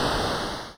make_coffee.wav